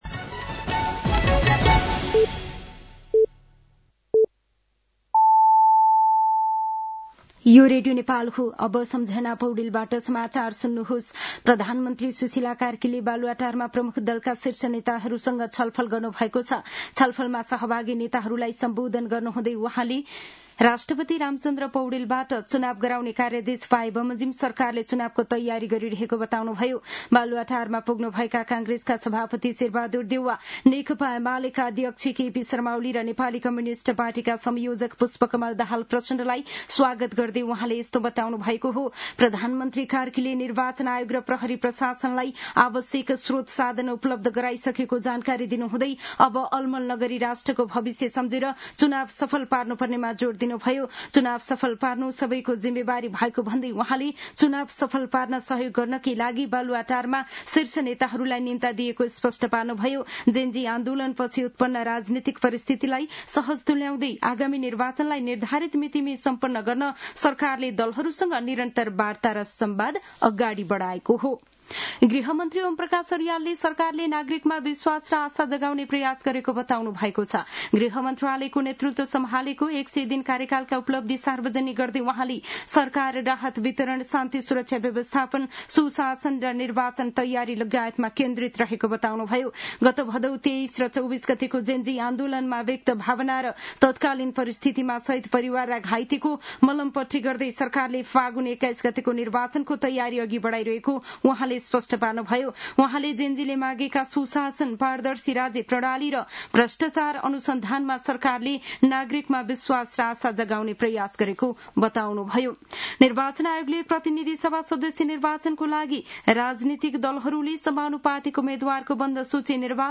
साँझ ५ बजेको नेपाली समाचार : १२ पुष , २०८२
5.-pm-nepali-news-1-4.mp3